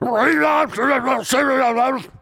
Звук разъяренного соседа из игры Как достать соседа (Neighbours From Hell)